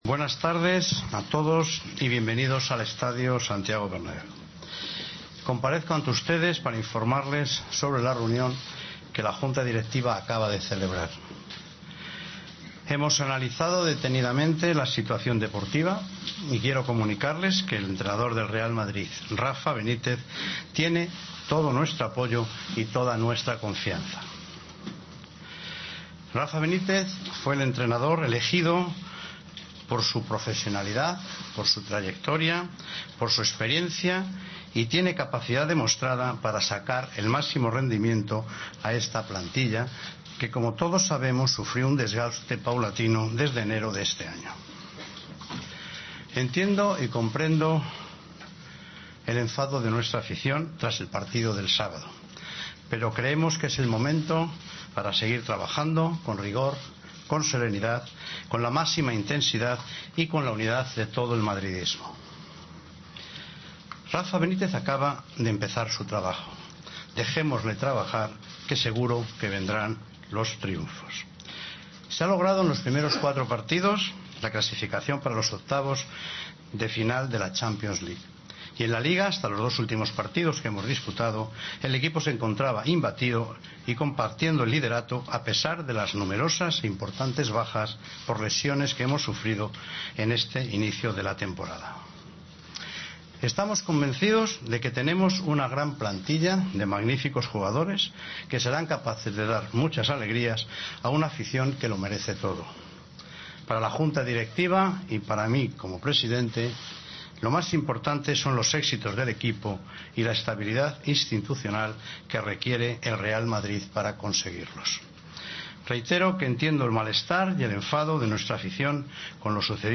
Comparecencia íntegra de Florentino Pérez en la que ratifica a Rafa Benítez
AUDIO: El presidente del Real Madrid ha dado una rueda de prensa en la que ha ratificado a Rafa Benítez y habló de la actualidad del...